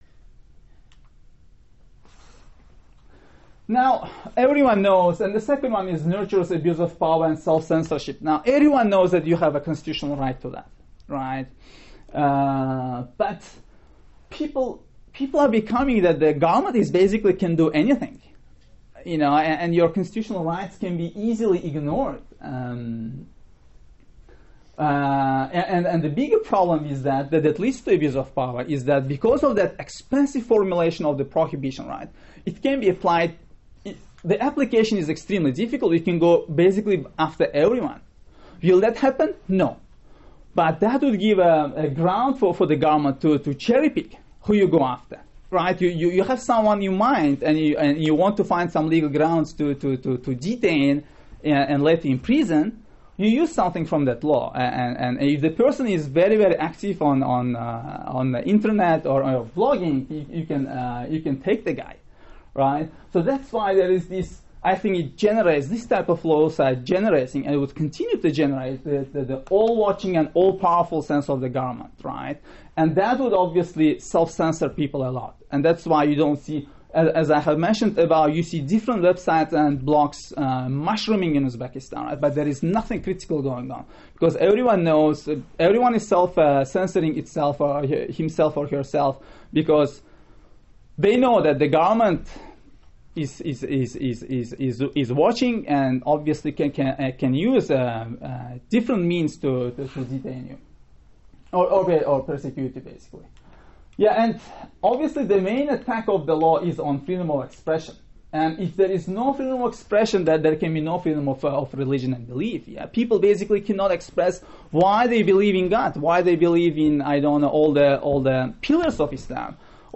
Regulation of blogger's activity in Uzbekistan. Panel at GW, Central Asia program. Part-III